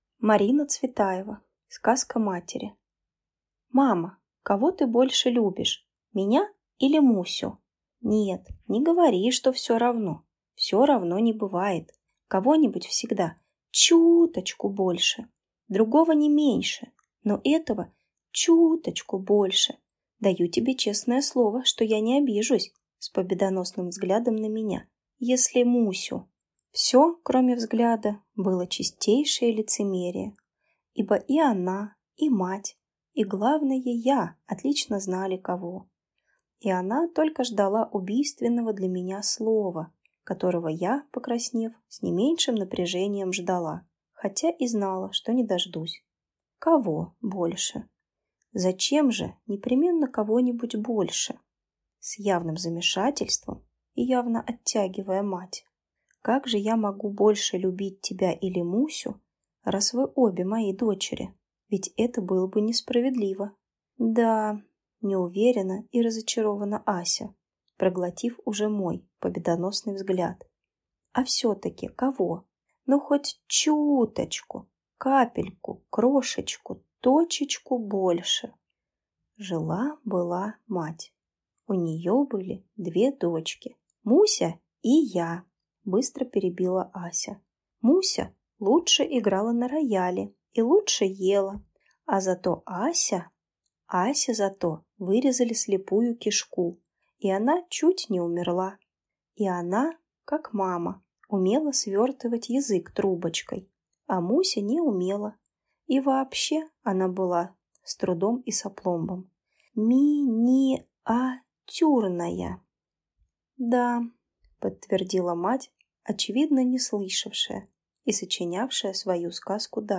Аудиокнига Сказка матери | Библиотека аудиокниг